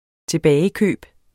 Udtale [ teˈbæːjəˌkøˀb ]